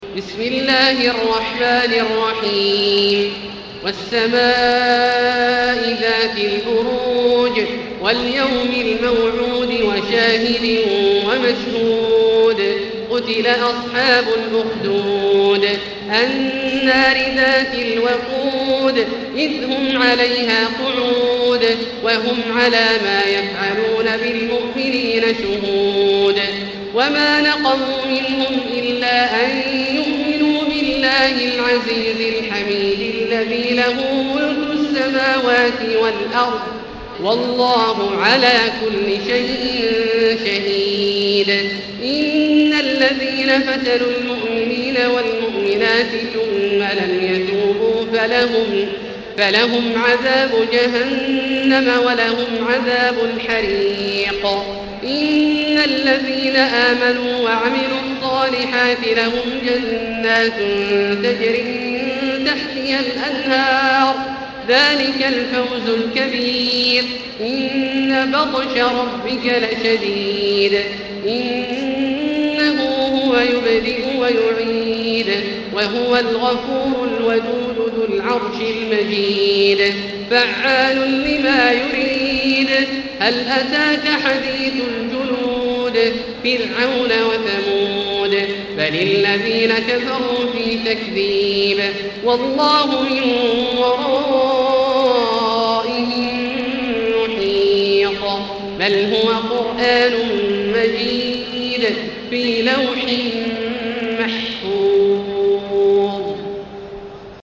المكان: المسجد الحرام الشيخ: فضيلة الشيخ عبدالله الجهني فضيلة الشيخ عبدالله الجهني البروج